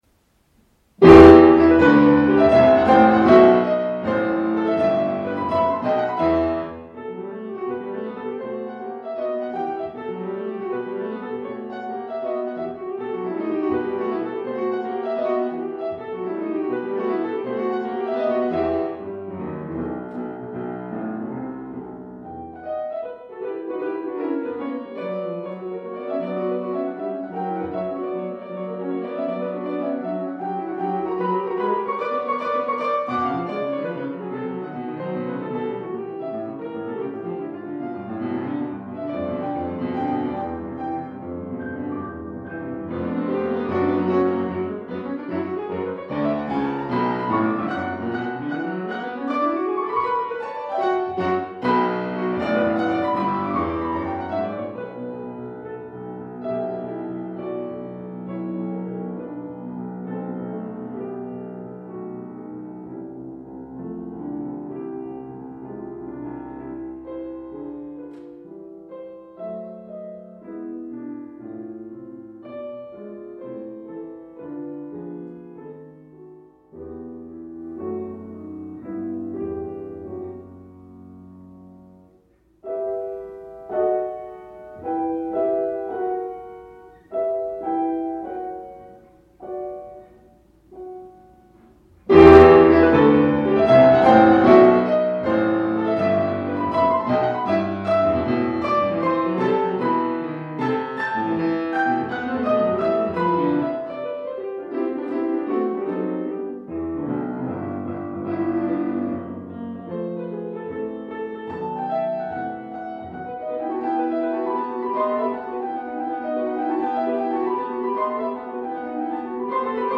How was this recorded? The purpose of this Master’s report is to analyze the works performed at my piano recital on March 24, 2020.